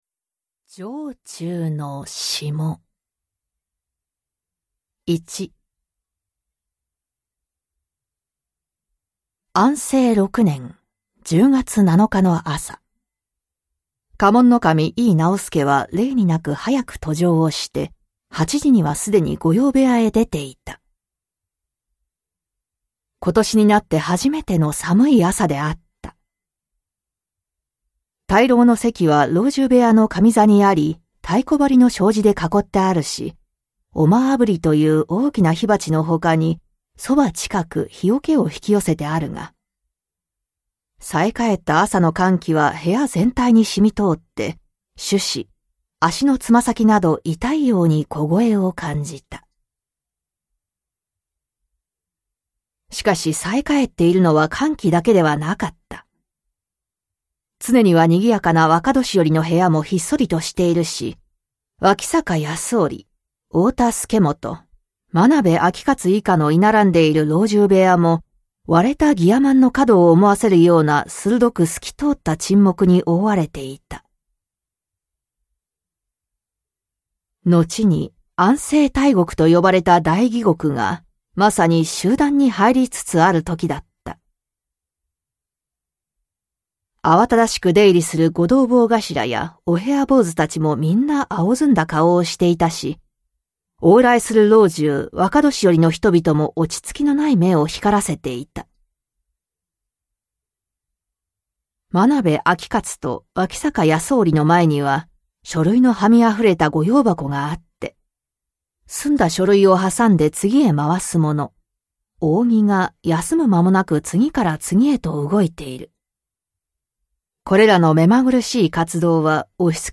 [オーディオブック] 城中の霜